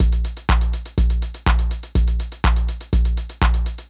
ENE Beat - Mix 5.wav